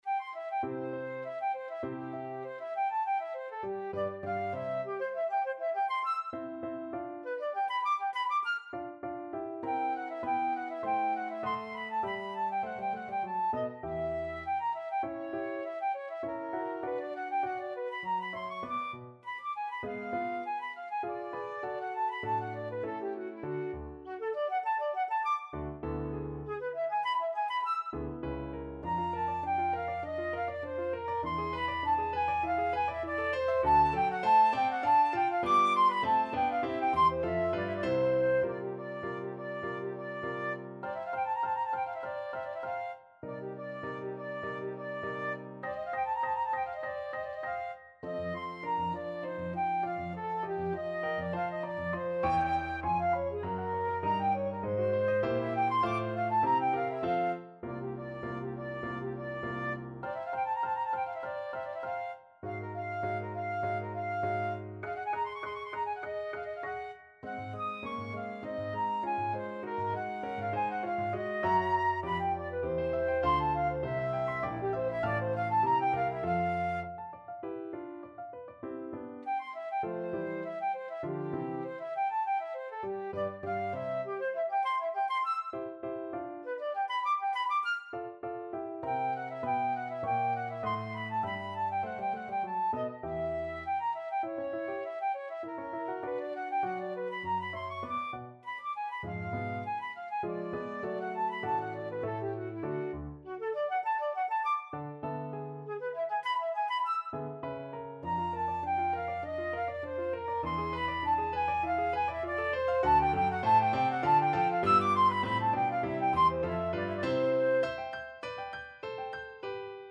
~ = 200 Allegro Animato (View more music marked Allegro)
2/2 (View more 2/2 Music)
Flute  (View more Intermediate Flute Music)
Classical (View more Classical Flute Music)